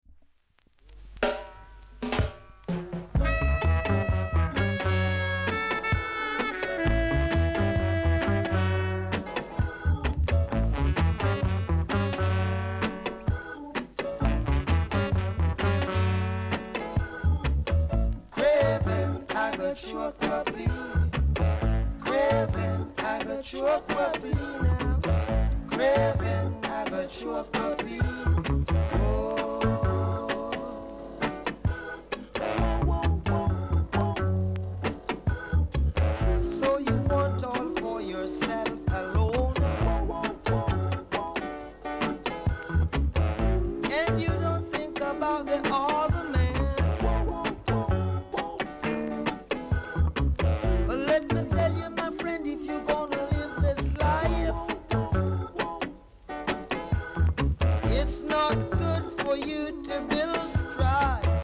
※小さなチリ、パチノイズが少しあります。